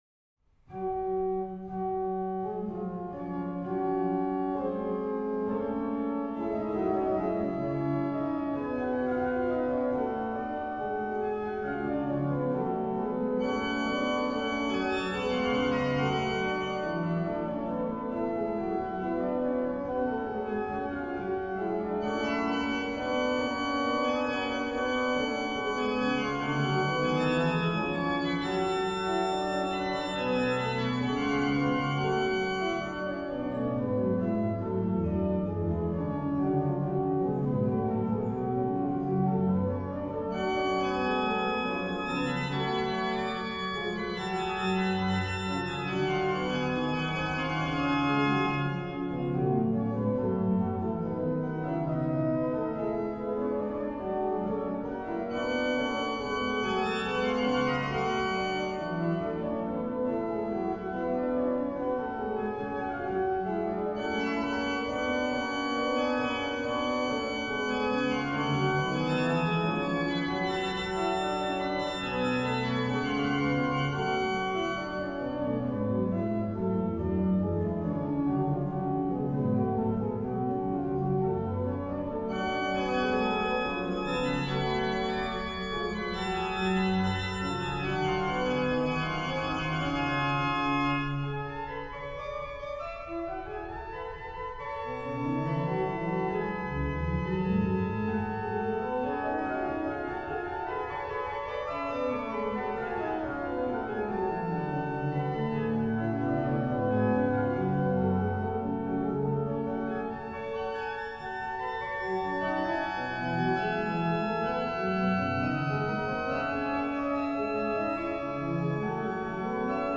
Registration   Pos: Qnt8, Oct4, Scharff